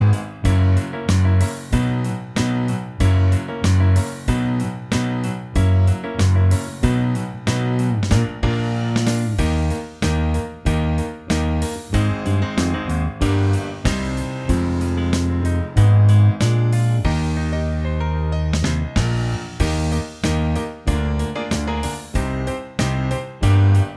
Minus Lead Guitar Rock 3:03 Buy £1.50